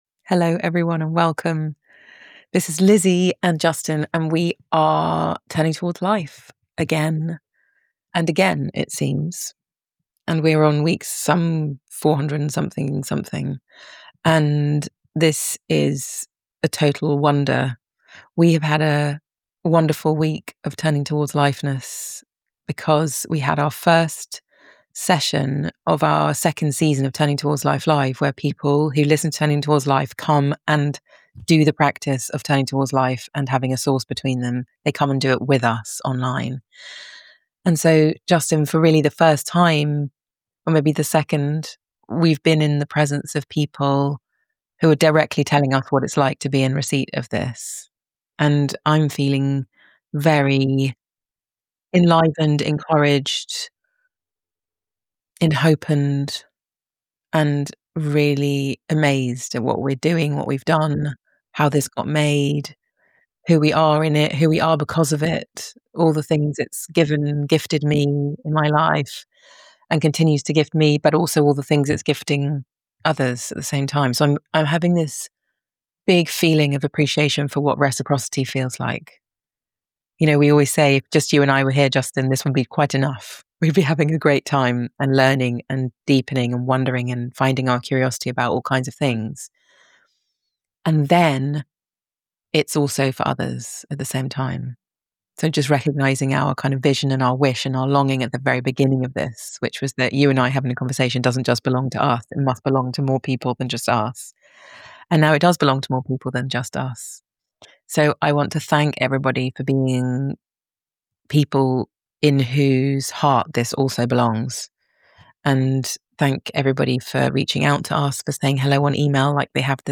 A conversation about the qualities that seem absent in us, that we long for so greatly, and the way they are already present in us because that is what humans are. How we find our courage, or integrity, or creativity or love not so much by trying to fill the absence from outside but by walking our way, patiently, into them in our everyday way of moving through life.